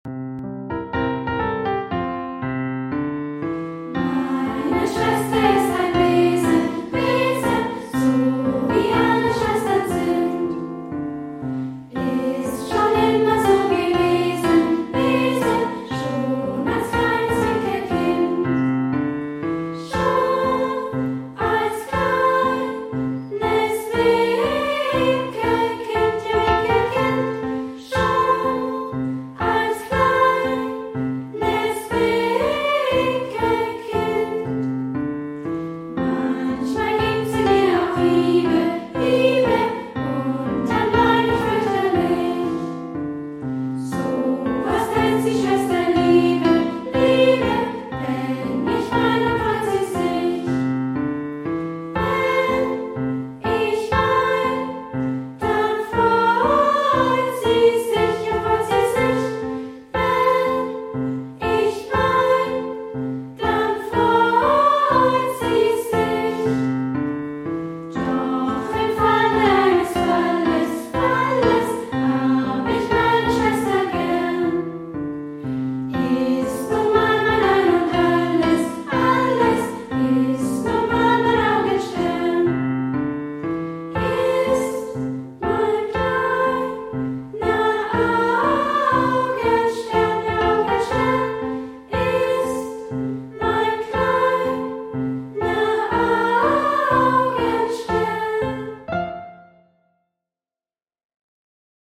Gesungen von: Kinder- und Jugendchor Cantemus, Feuchtwangen
Klavier